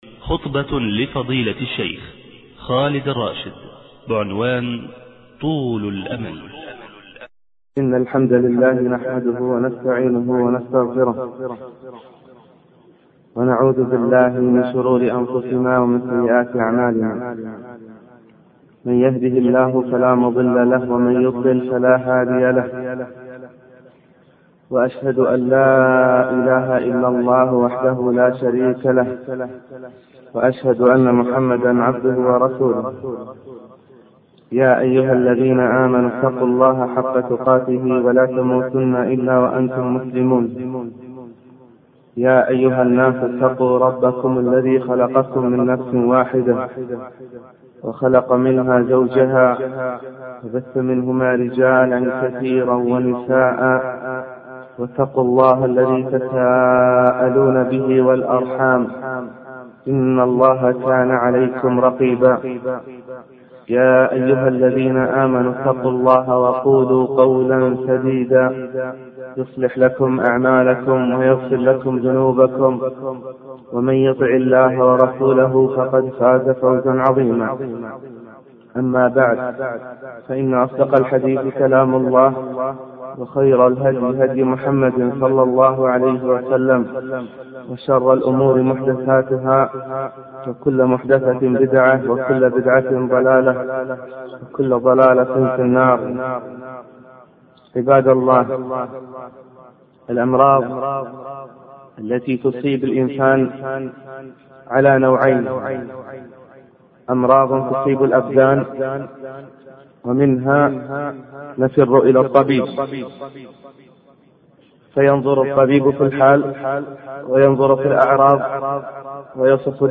الباب الأول: الحمد والثناء والتمهيد للخطبة